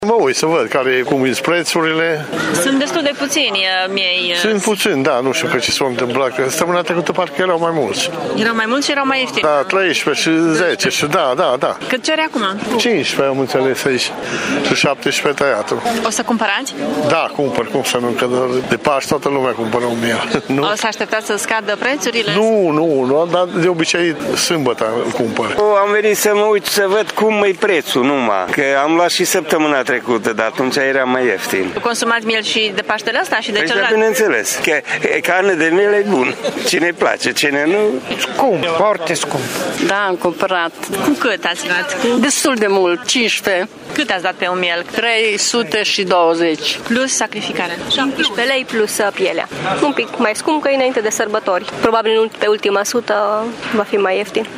Crescătorii de oi au refuzat să spună mai multe, în schimb, oamenii care au venit să cumpere sau să se intereseze de mersul pieței, sunt foarte nemulțumiți: